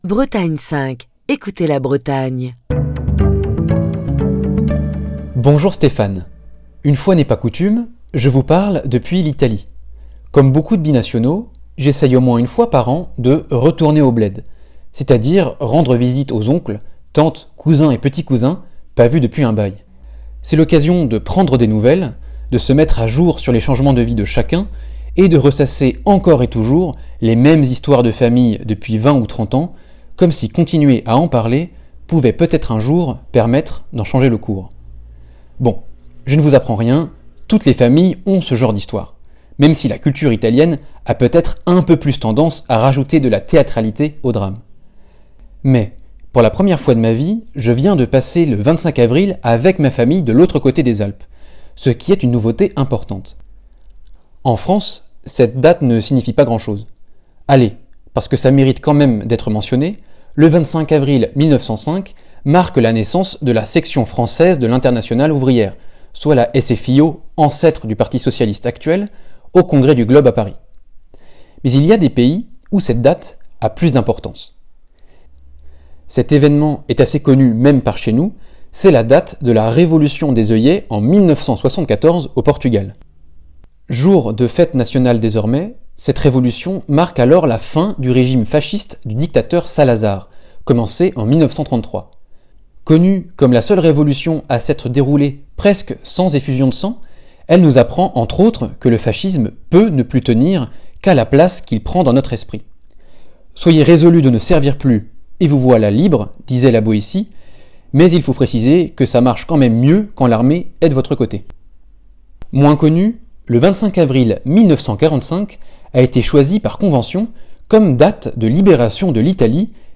Chronique du 29 avril 2024.